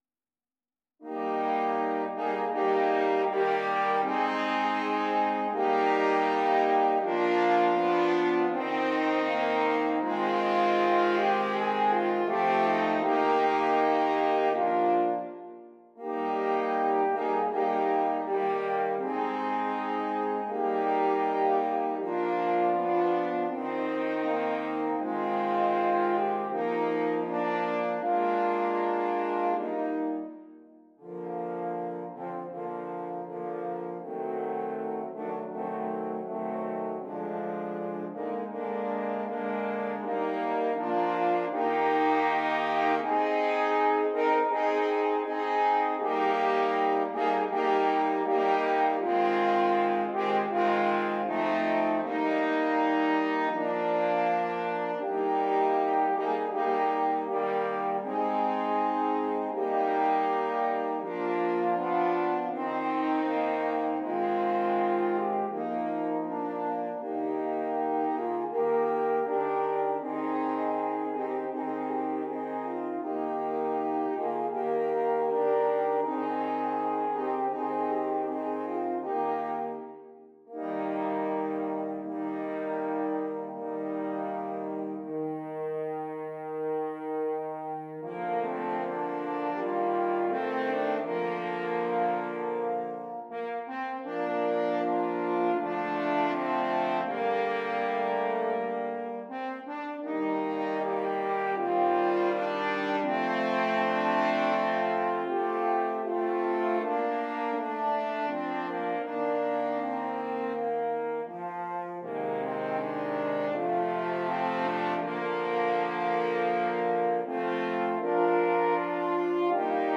4 F Horns